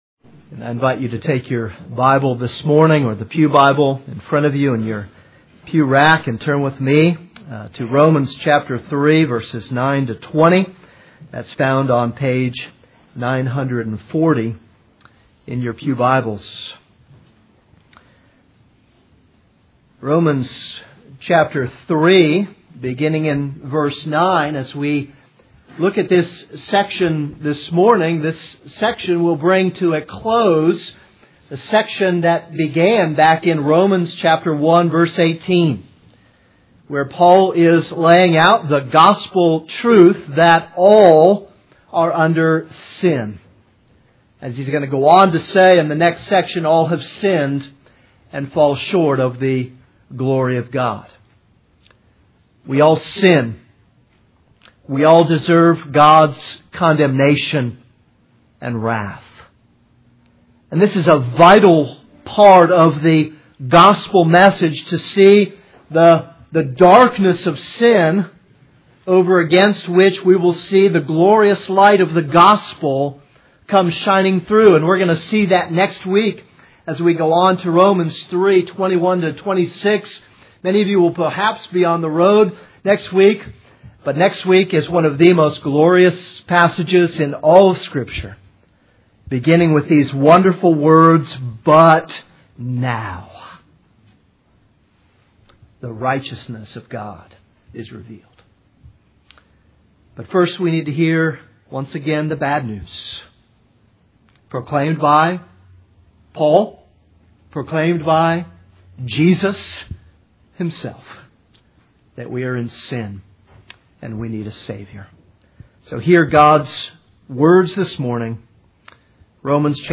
This is a sermon on Romans 3:9-20.